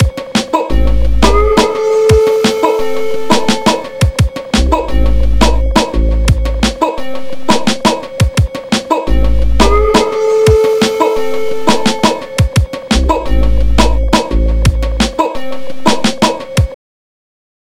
Pushed myself to make a quick beat.
I did some minor chopping and that was about it for now.